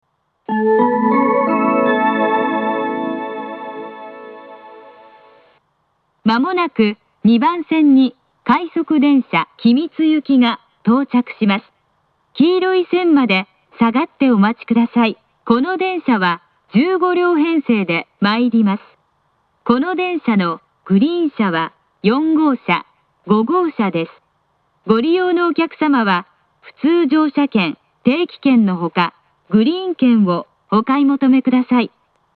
小ＶＯＳＳ時の音声
２番線接近放送